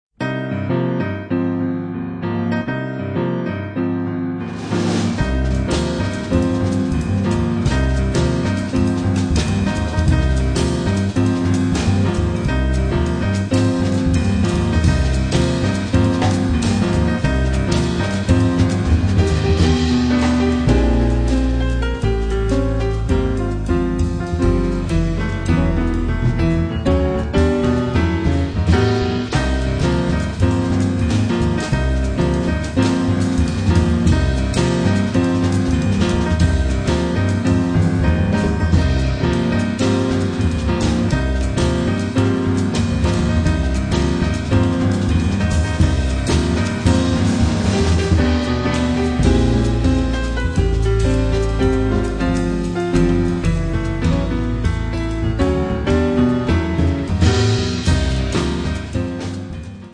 pianoforte
contrabbasso
batteria
ma sempre spruzzato di uno swing ben organizzato.